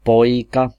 Ääntäminen
Synonyymit pojk Ääntäminen Tuntematon aksentti: IPA: /soːn/ IPA: /suːn/ Haettu sana löytyi näillä lähdekielillä: ruotsi Käännös Ääninäyte Substantiivit 1. poika Artikkeli: en .